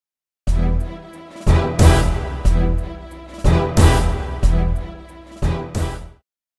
Im folgenden Audiobeispiel (Style MovieSoundtrack Variation B) enthält das Break gleich zu Taktbeginn in den Begleitstimmen ein Sforzato:
Beispiel 3a (Break Fill vor oder exakt am Taktbeginn ausgelöst)